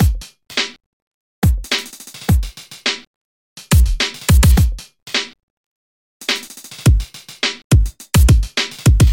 旧学校 鼓声循环7
描述：嘻哈风格，请欣赏！！。
Tag: 105 bpm Hip Hop Loops Drum Loops 1.54 MB wav Key : Unknown